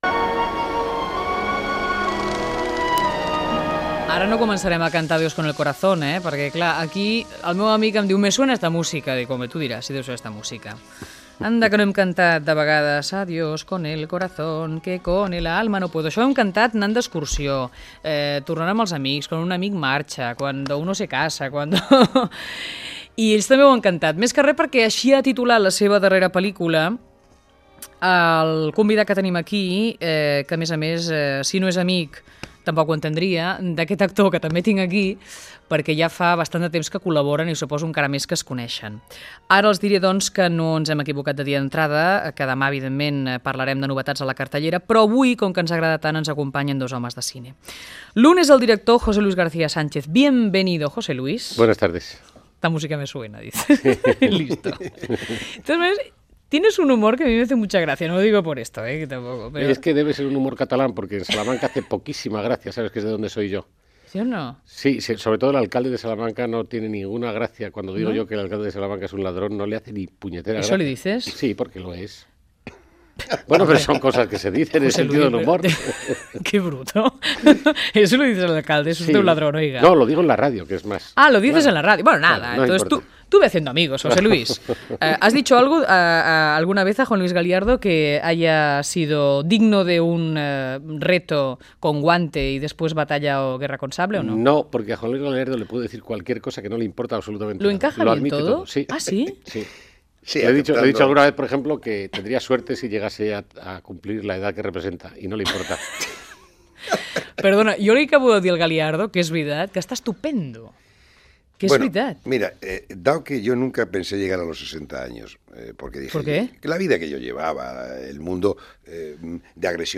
Fragment d'una entrevista a José Luis García Sánchez i Juan Luis Galiardo, director i actor de la pel·lícula "Adiós con el corazón".
Entreteniment